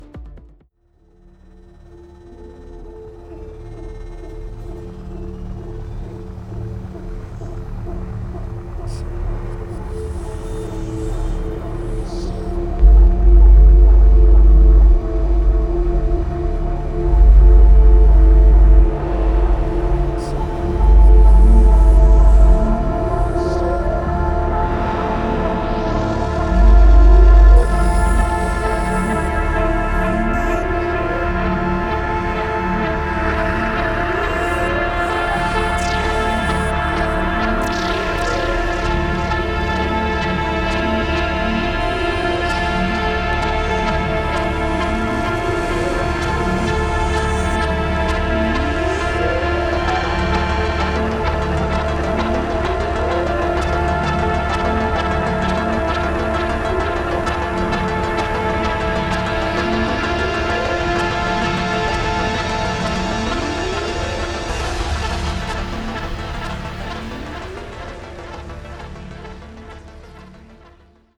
deeply romantic techno
experimental droning